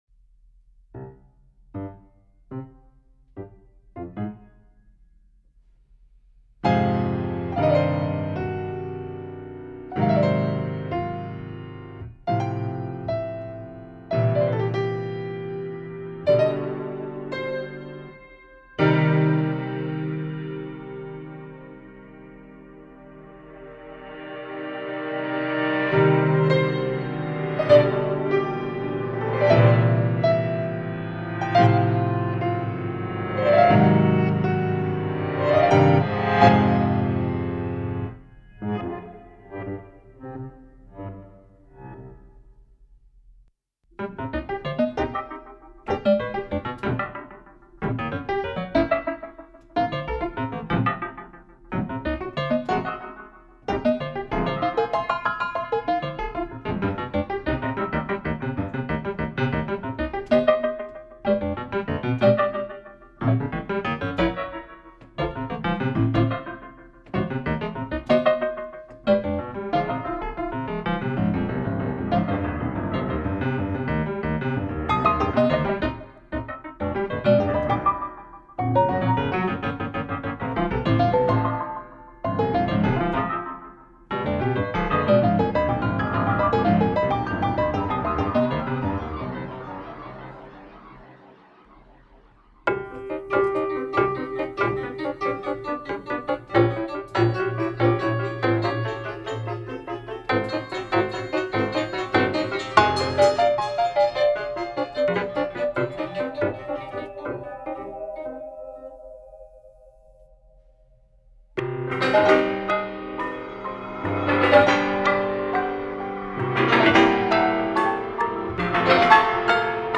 Beethoven32VariationsCminor.mp3